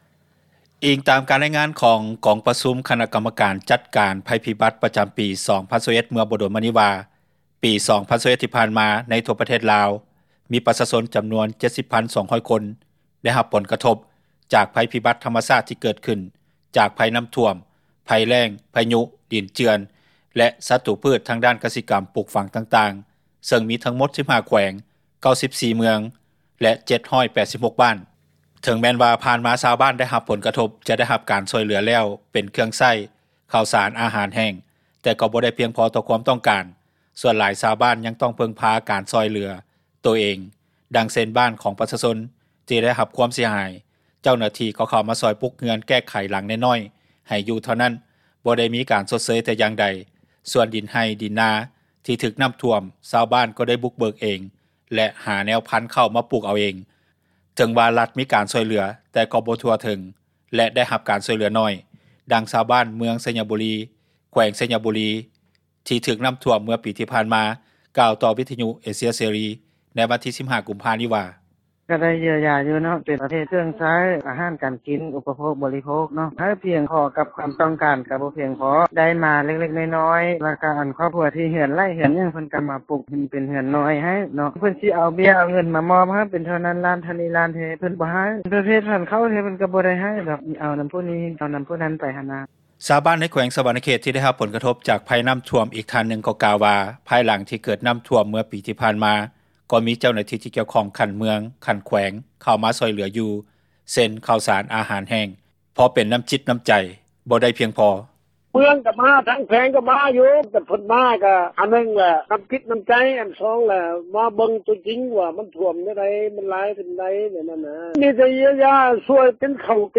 ດັ່ງຊາວບ້ານ ເມືອງໄຊຍະບູຣີ ແຂວງໄຊຍະບູຣີ ທີ່ຖືກນໍ້າຖ້ວມເມື່ອປີ ຜ່ານມາກ່າວຕໍ່ວິທຍຸເອເຊັຽເສຣີໃນວັນທີ 15 ກຸມພາ ນີ້ວ່າ: